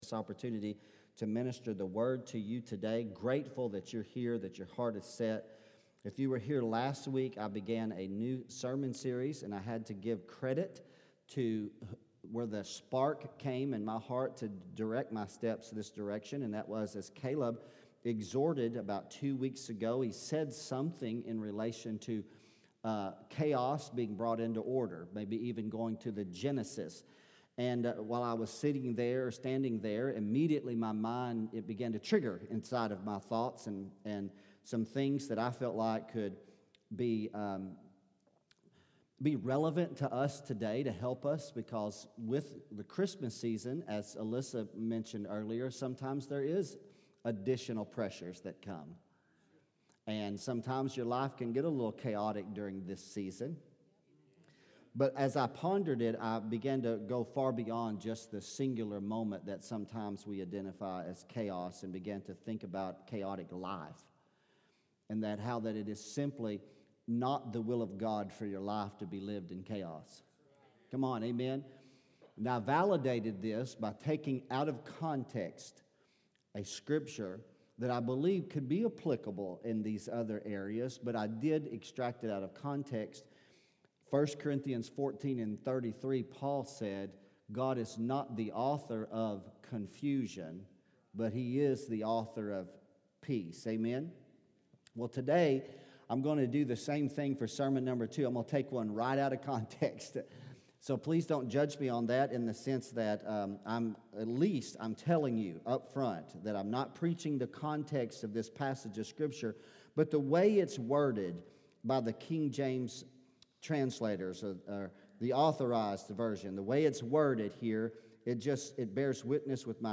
Sermons | The Assembly Heber Springs